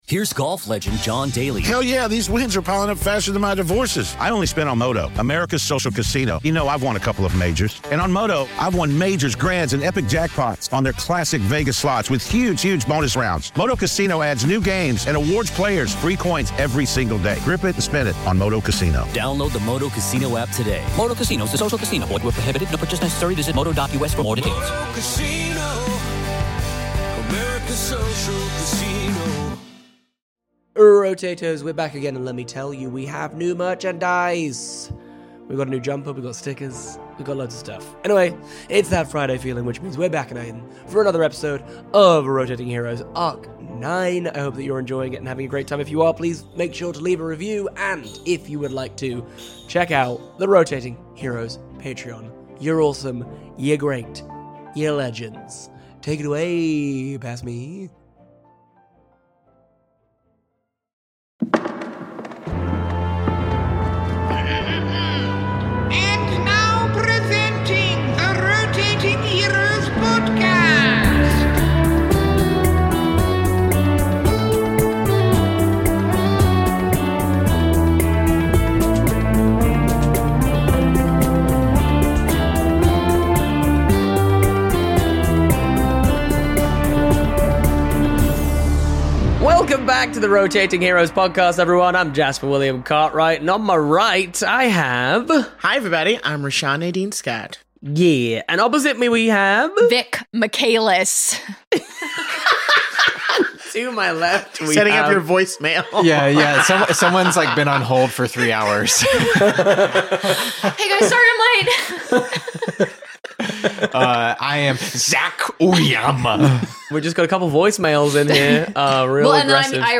Zac Oyama is playing Jin
Vic Michaelis as Fhinne Foode